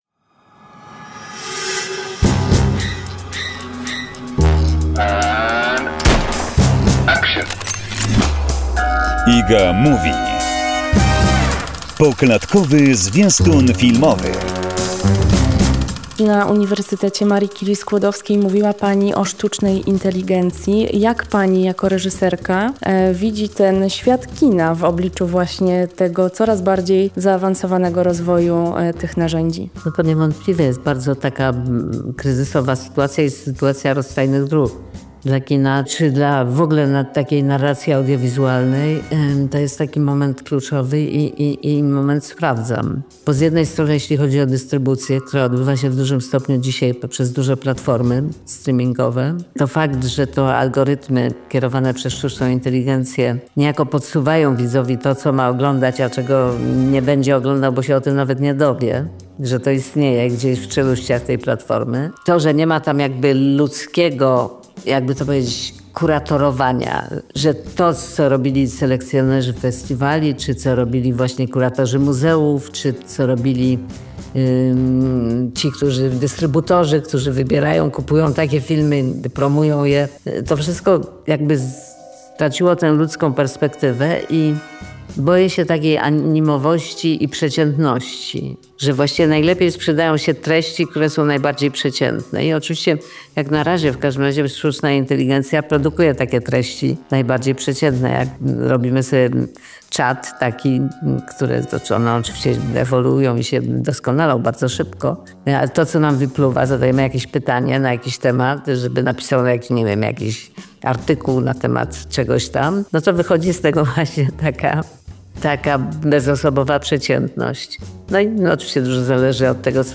rozmowa z Agnieszką Holland